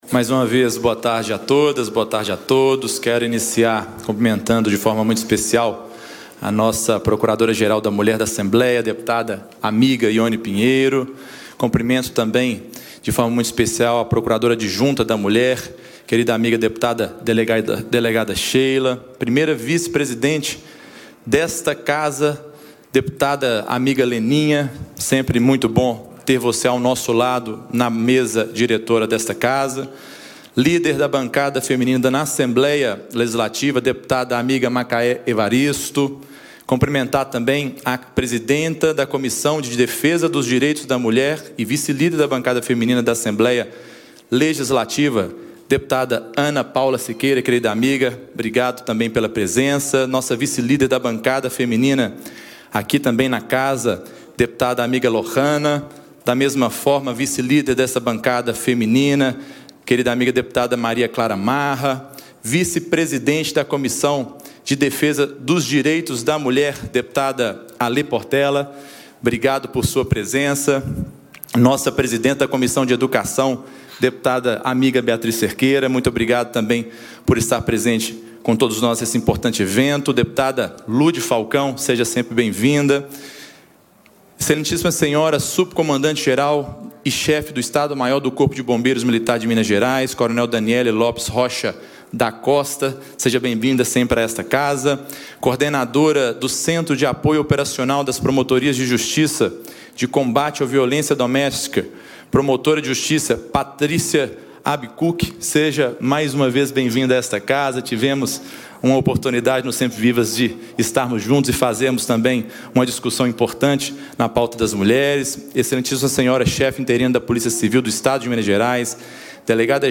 Ele destacou ações da ALMG para reverter esse quadro durante posse do comando da Procuradoria-Geral da Mulher e instalação oficial da bancada feminina.
Íntegra - Deputado Tadeu Martins Leite lamenta Minas estar no topo do ranking de crimes contra a mulher